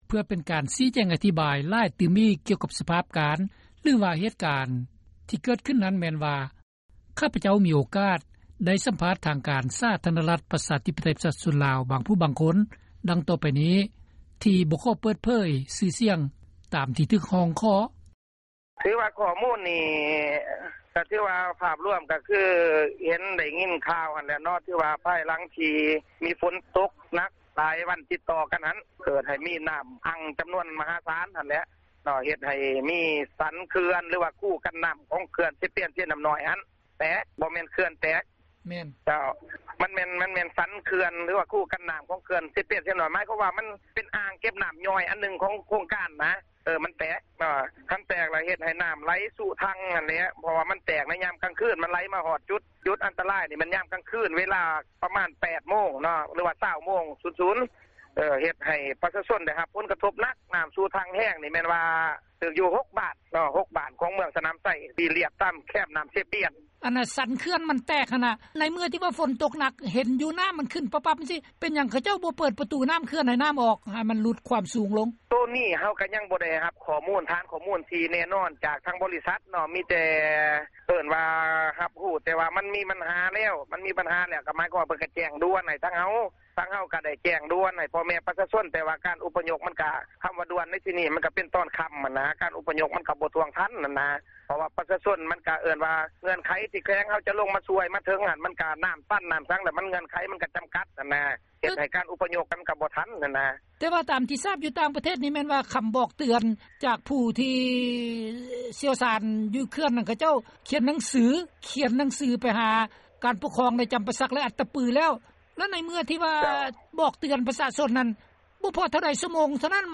Xov xwm los ntawm Nplog ib tug neeg tshaj xov xwm zejzog tham txog lub pas dej tauv Xe Pien Xe Nam Noy tawg ntawm xeev Attapeu (Tham ua lus Nplog).